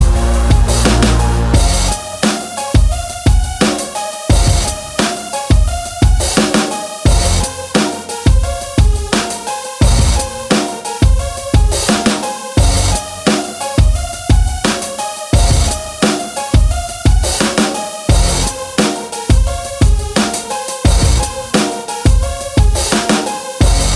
no Backing Vocals R'n'B / Hip Hop 3:51 Buy £1.50